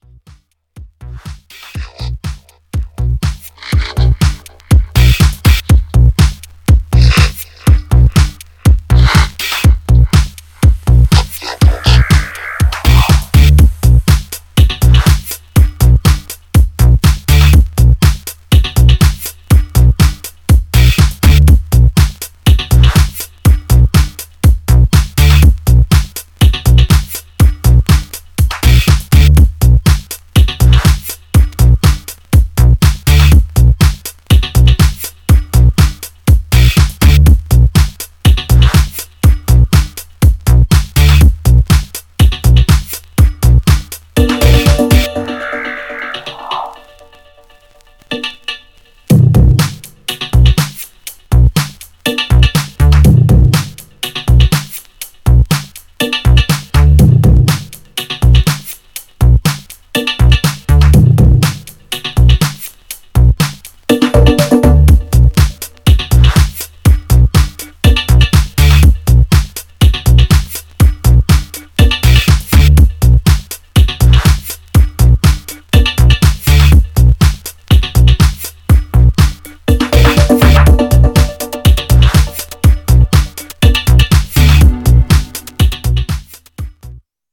Styl: Electro, House, Breaks/Breakbeat